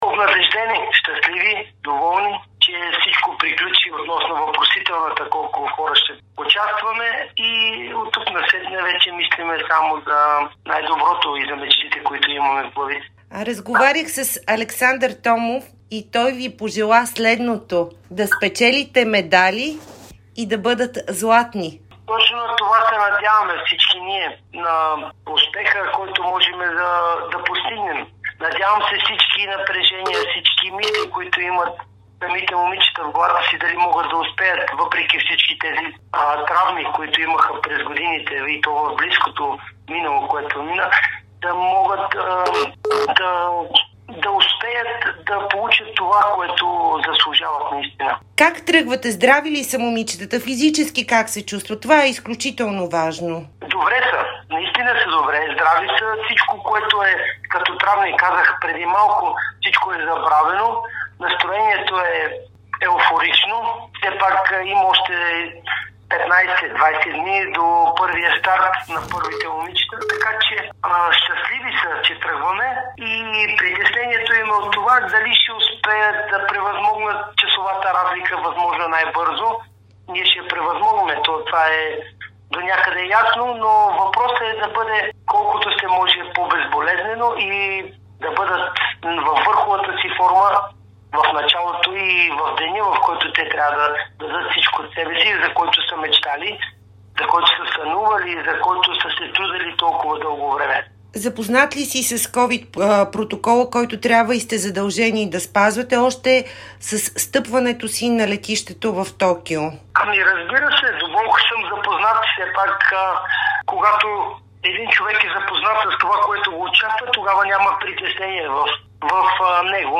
интервюто